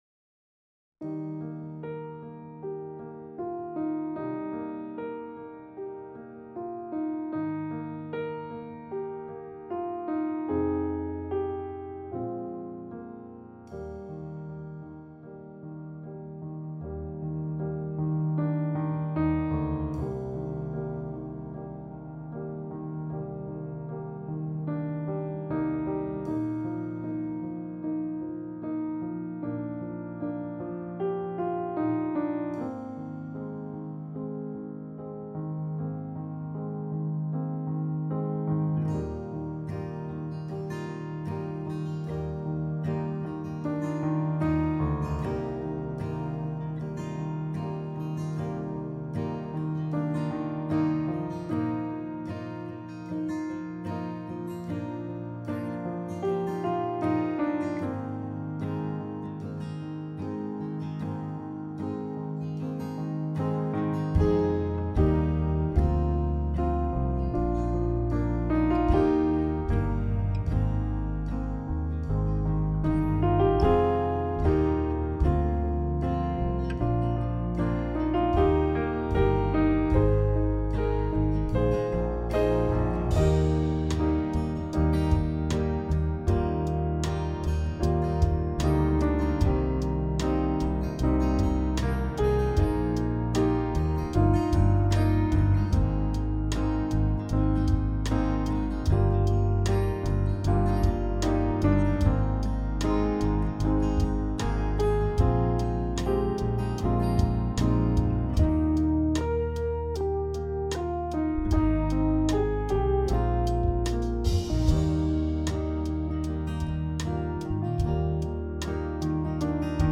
Accompaniment Track